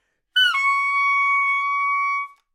单簧管单音（吹得不好） " 单簧管 C6 badattackgallo
描述：在巴塞罗那Universitat Pompeu Fabra音乐技术集团的goodsounds.org项目的背景下录制。
标签： 纽曼-U87 单簧管 单注 多重采样 好声音 Csharp6
声道立体声